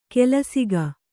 ♪ kelasiga